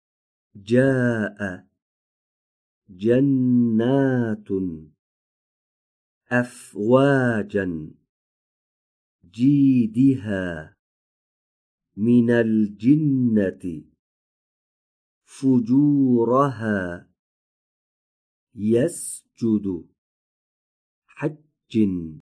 🔸ابتدا به تلفظ حرف «ج» در این کلمات گوش فرا دهید و سپس آنها را تکرار کنید.